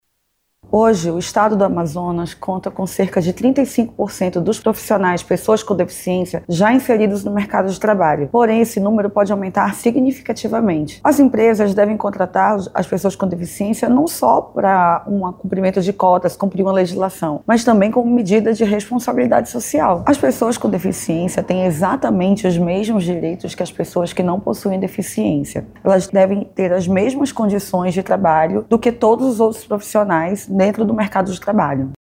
O “Mais Acesso Conecta” busca aumentar o número de pessoas empregadas em 2025, como destaca a juíza do Trabalho, Carla Nobre.